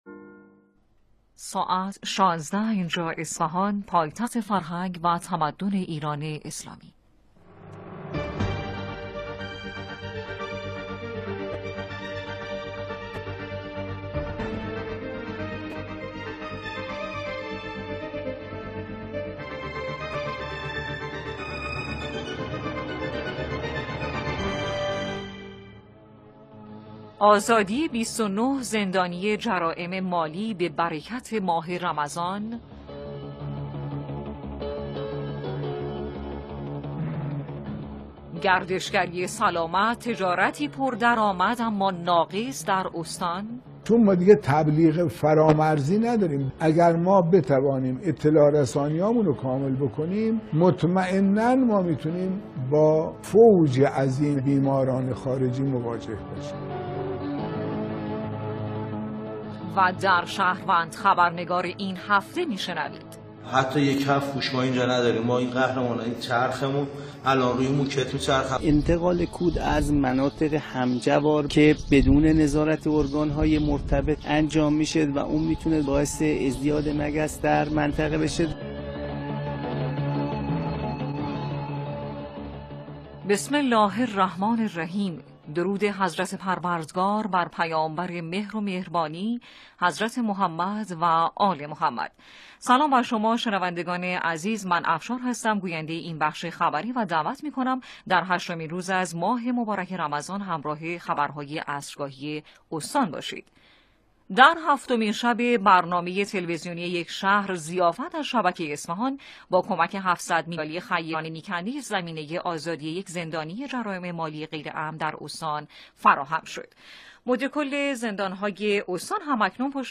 انعکاس مهمترین رویدادها از بخش خبری عصرگاهی رادیو